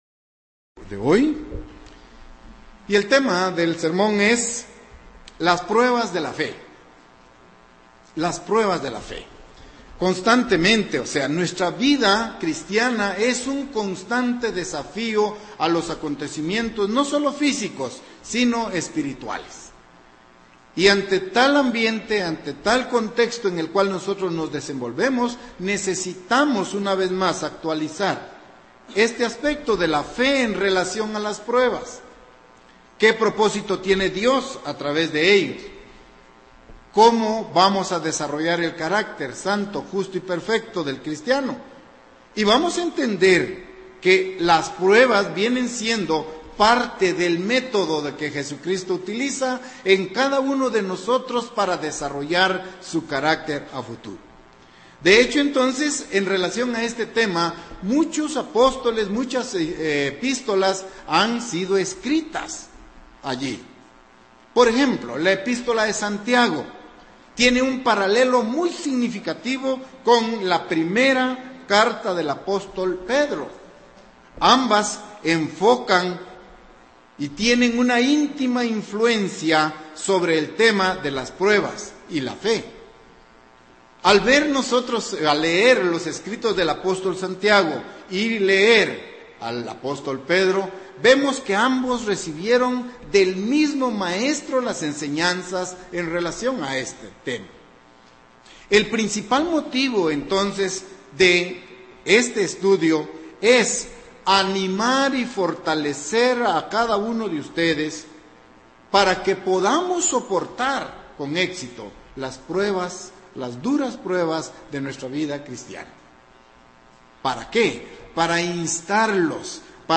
Sermones
Given in Ciudad de Guatemala